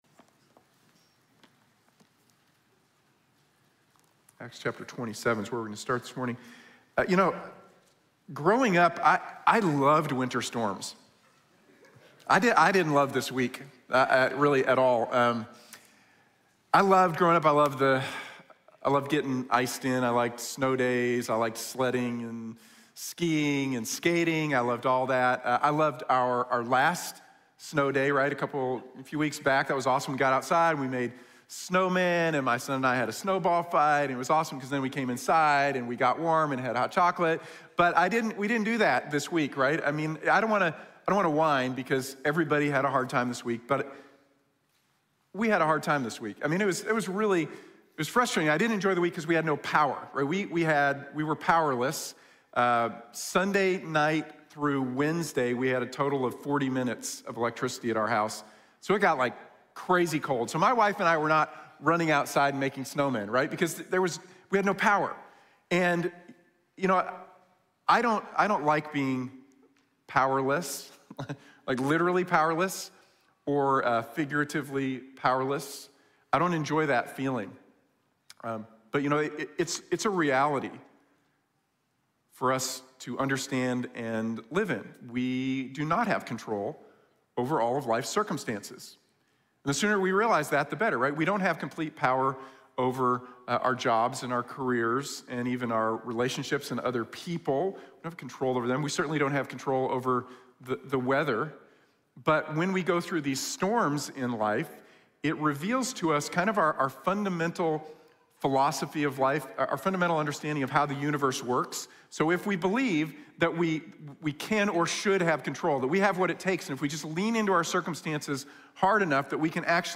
Lessons from the Storm | Sermon | Grace Bible Church